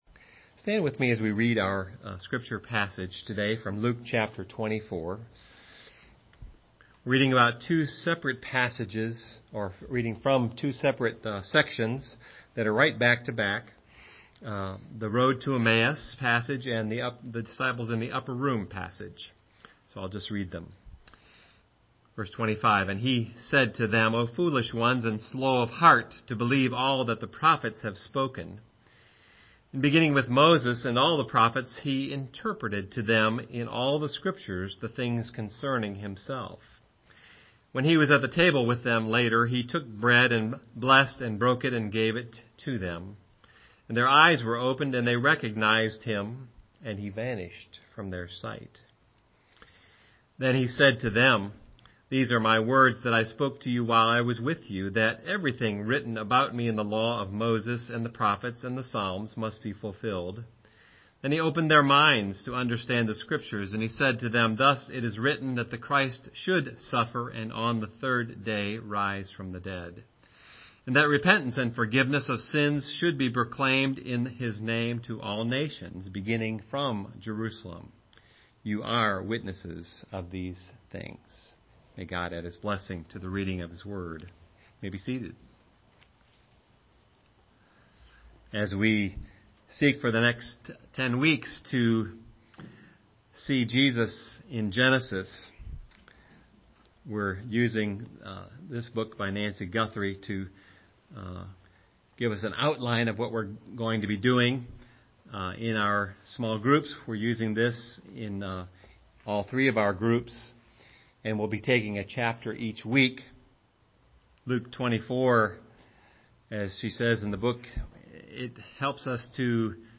Home › Sermons › Sermons by Year › 2012 › Recognizing Jesus as the Christ (Luke 24:25-27, 30-31, 44-48)